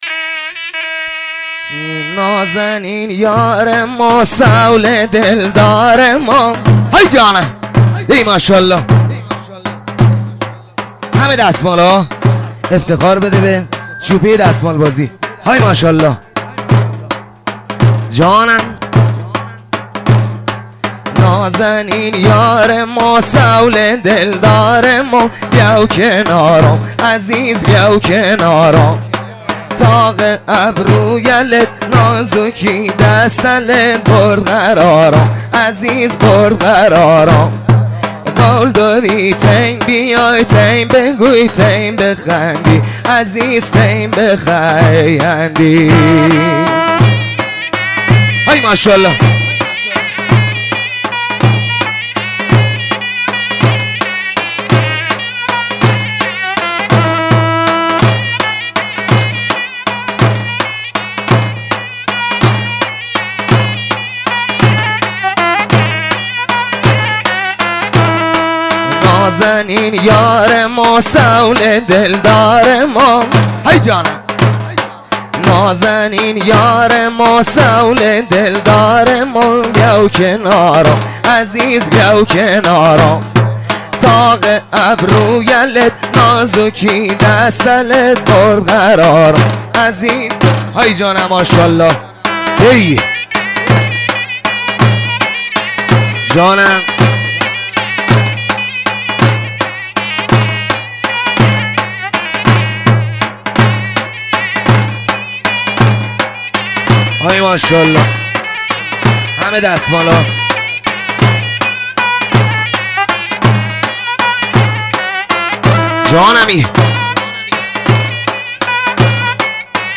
اهنگ لری بختیاری